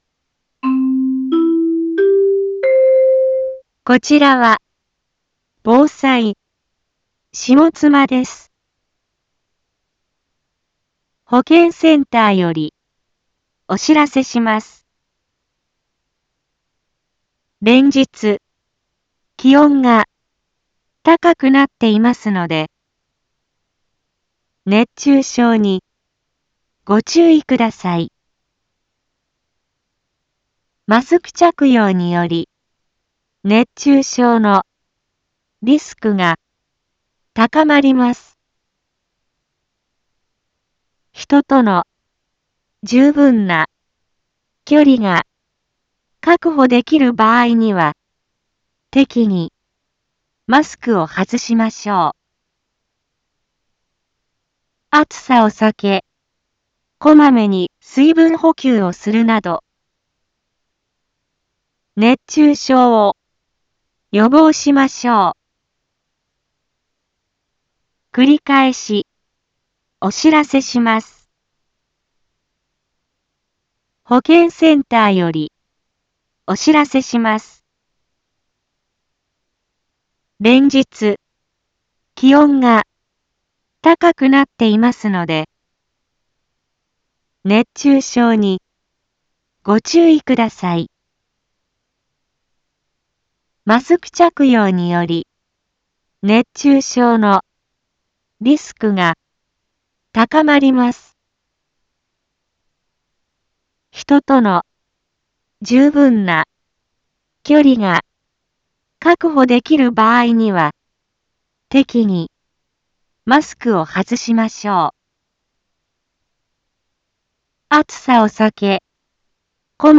Back Home 一般放送情報 音声放送 再生 一般放送情報 登録日時：2022-06-28 11:02:24 タイトル：熱中症注意のお知らせ インフォメーション：こちらは、防災、下妻です。